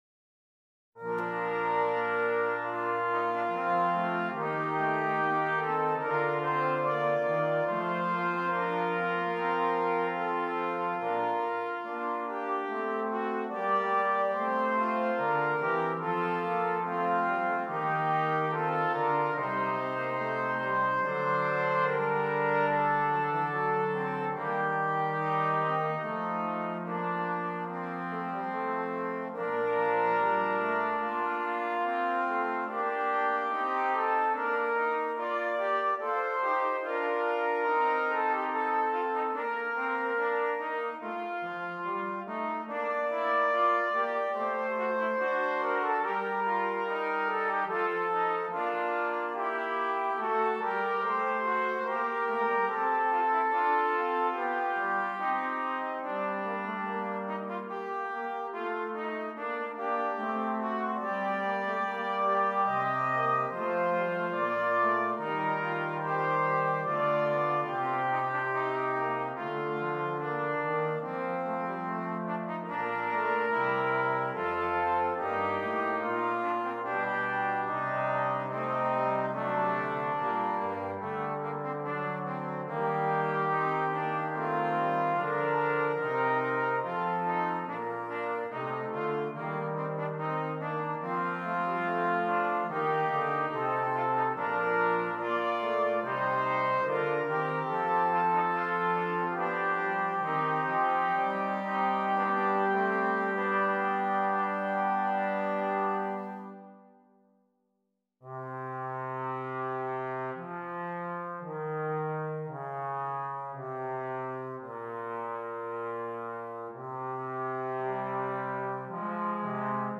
Brass Quartet